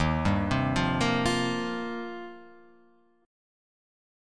D#6add9 Chord